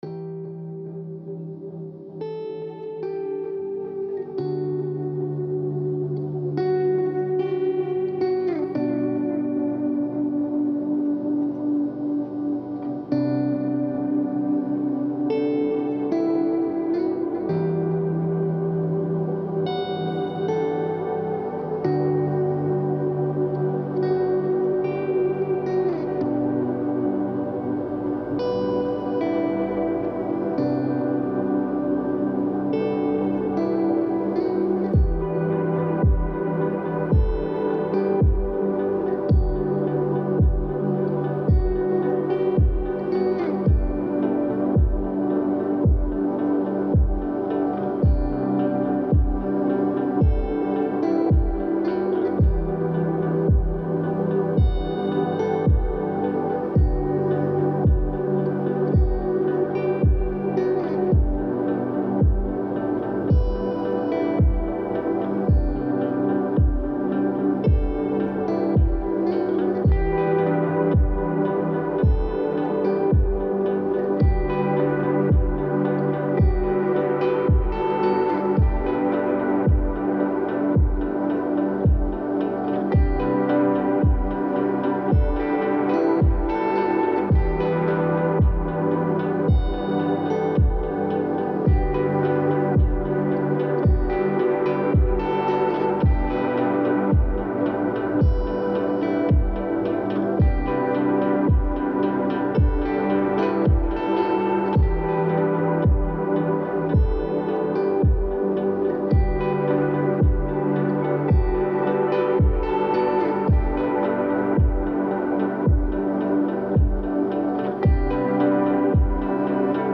پست راک عمیق و تامل برانگیز موسیقی بی کلام